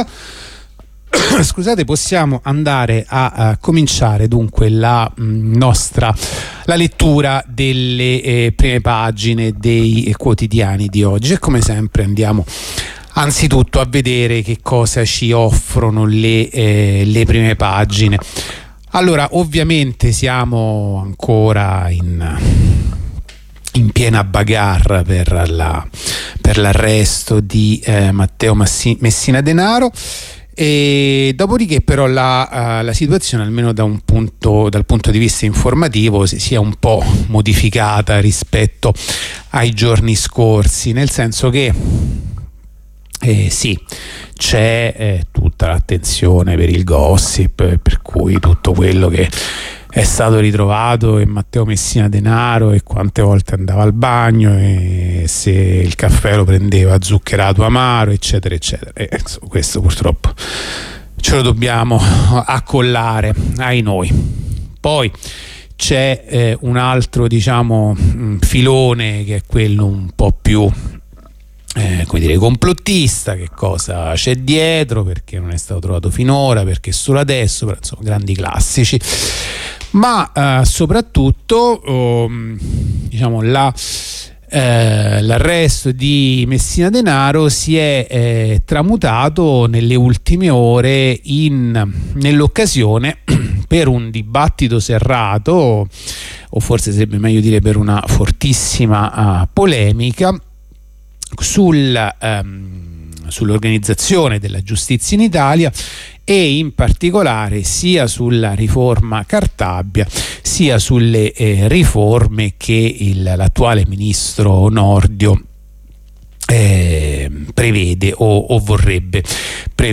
La rassegna stampa di radio onda rossa andata in onda venerdì 20 gennaio 2023